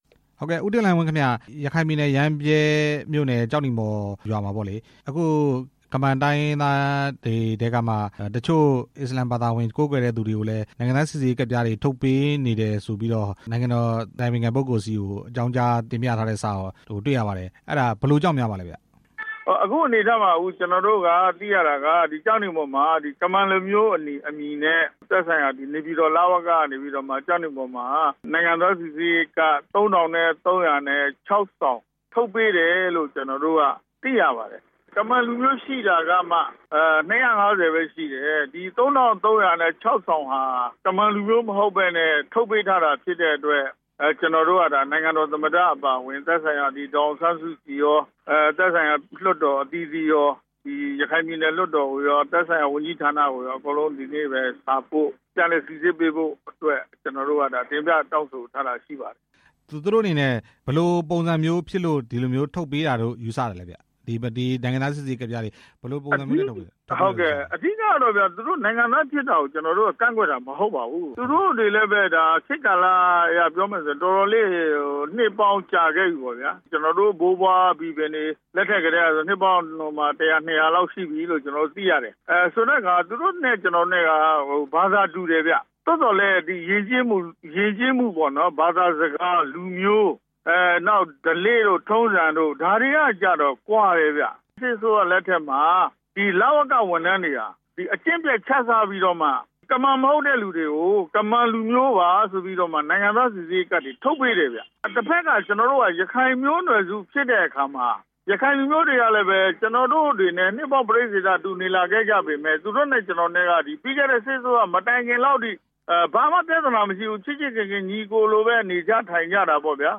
ကျောက်နီမော်ကျေးရွာ နိုင်ငံသားစိစစ်ရေးကိစ္စ မေးမြန်းချက်